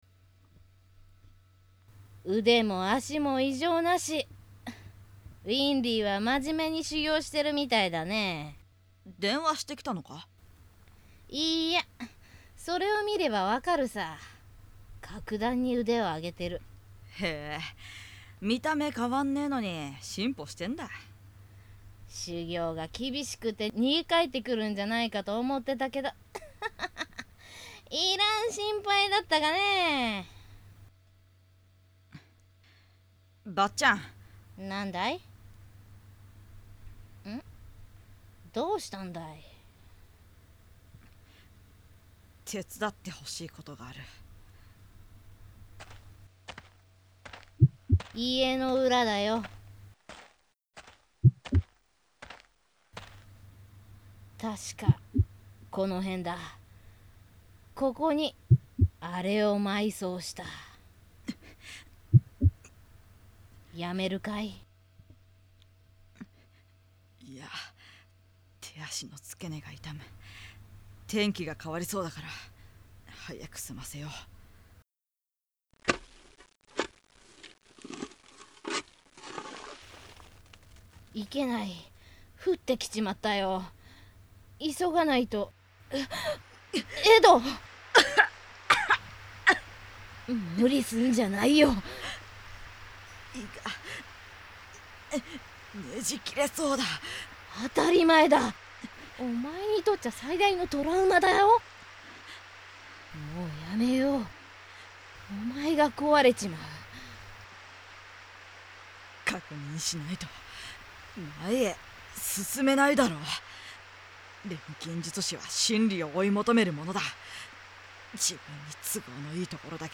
版権掛け合い
＊すでにアニメ化（メディア化）していたりしても、声優さんのイメージは考えずに自分なりのイメージで演じています。